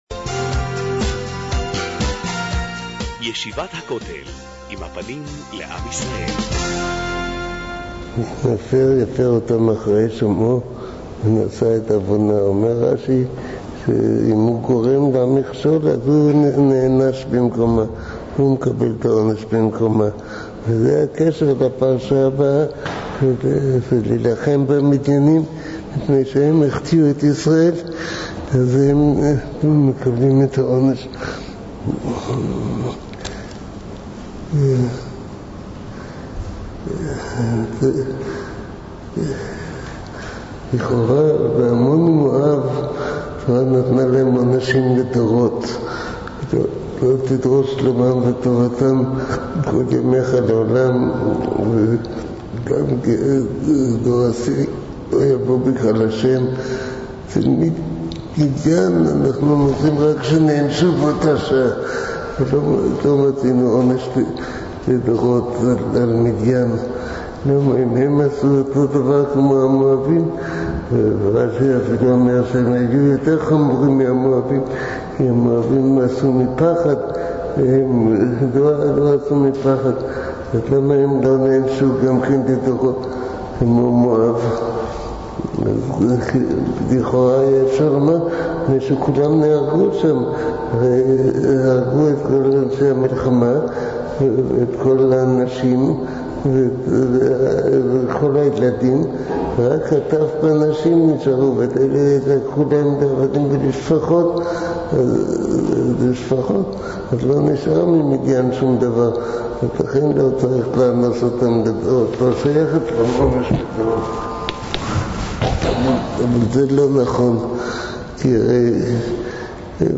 שיעור לפרשת מטות